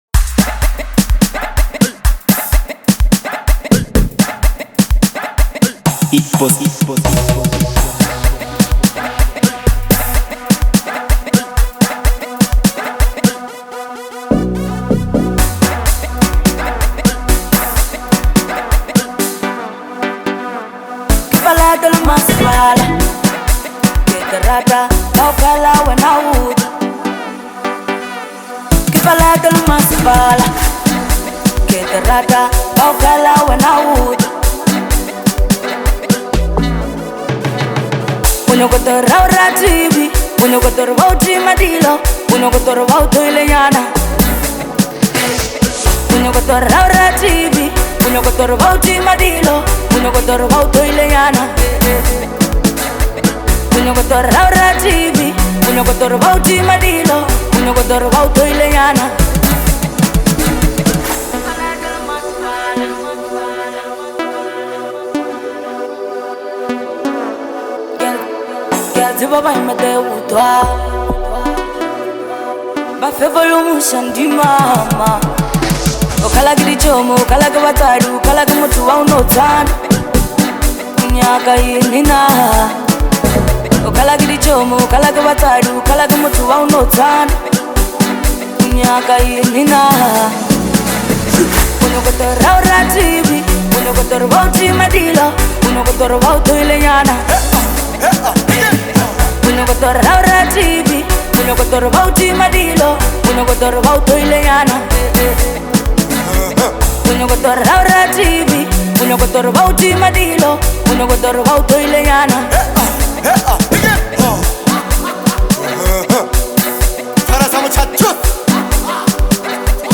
vibrant Lekompo anthem
Lekompo